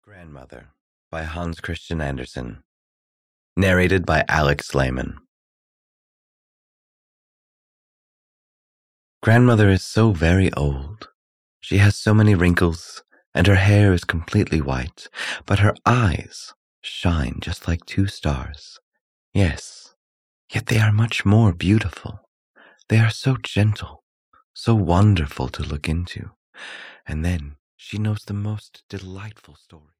Grandmother (EN) audiokniha
Ukázka z knihy